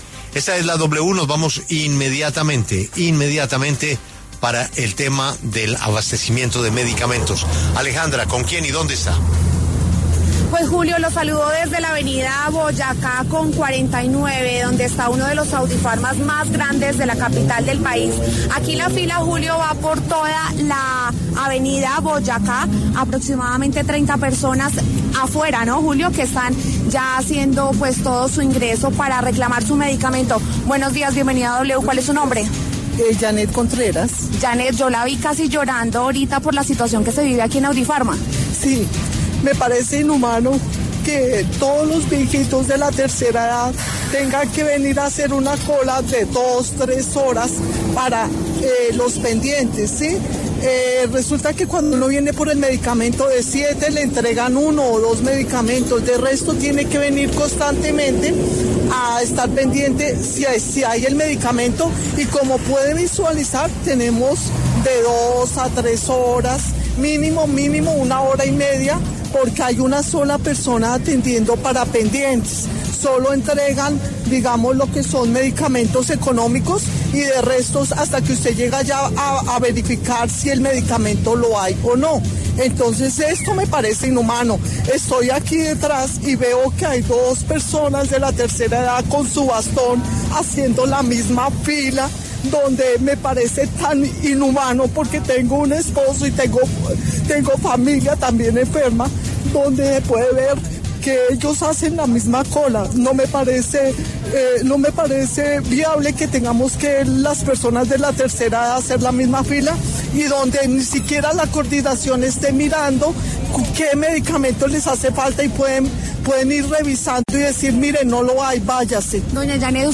La W hace un recorrido por los principales puntos de Audifarma en Bogotá, escuchando denuncias y opiniones de la ciudadanía sobre la crisis de medicamentos en la que está el país.